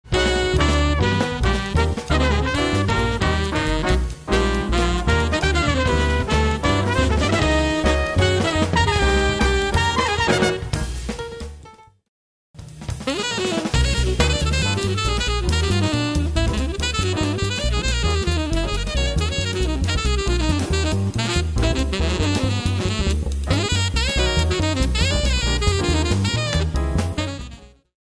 Tenor Saxophone: